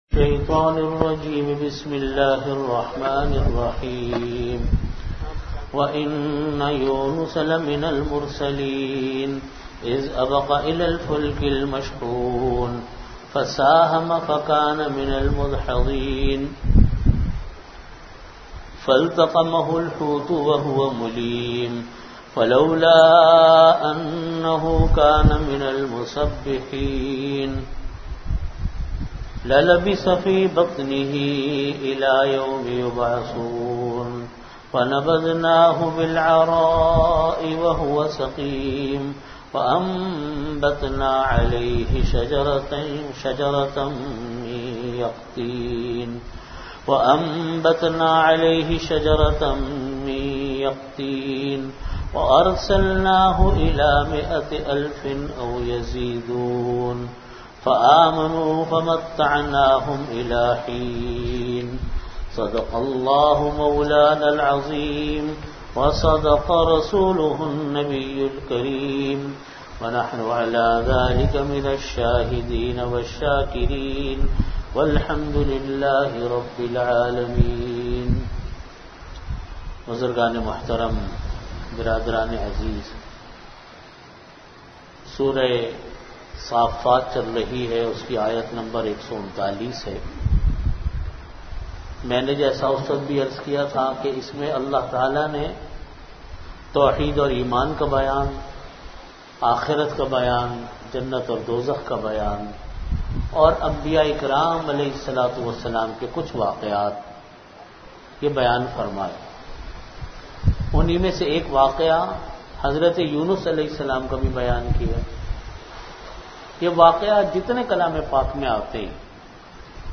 Audio Category: Tafseer
Venue: Jamia Masjid Bait-ul-Mukkaram, Karachi